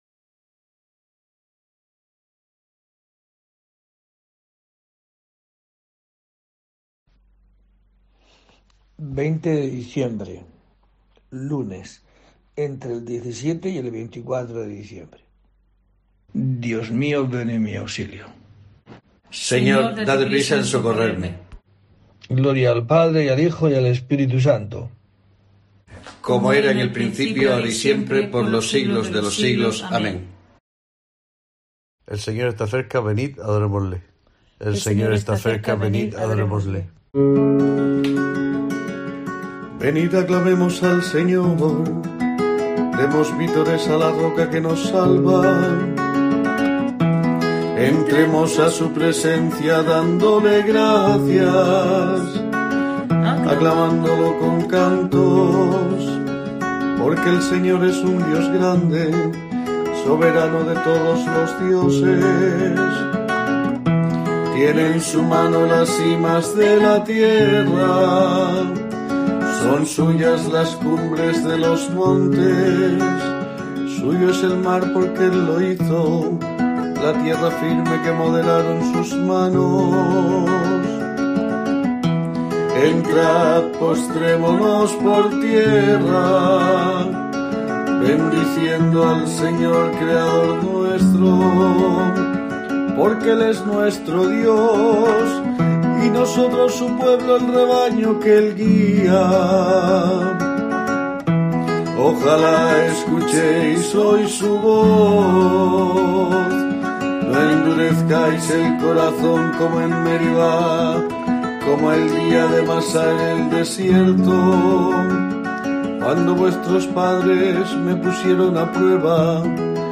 20 de diciembre: COPE te trae el rezo diario de los Laudes para acompañarte